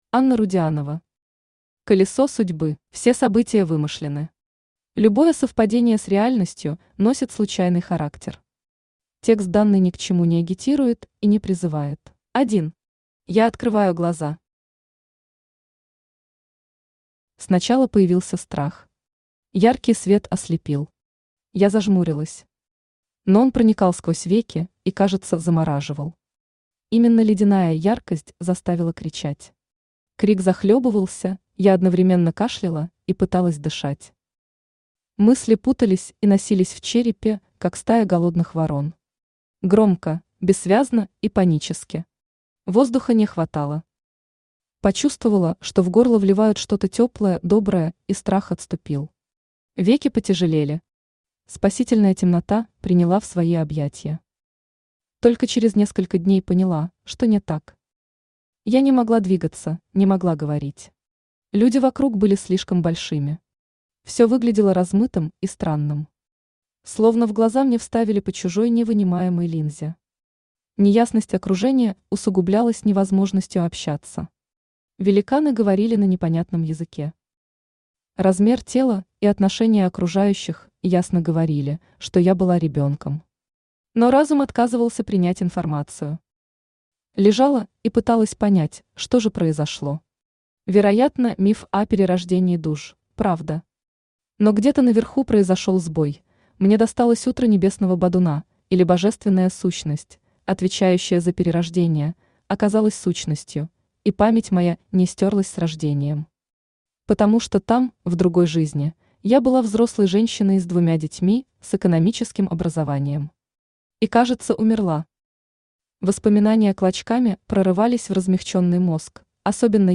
Аудиокнига Колесо Судьбы | Библиотека аудиокниг
Aудиокнига Колесо Судьбы Автор Анна Игоревна Рудианова Читает аудиокнигу Авточтец ЛитРес.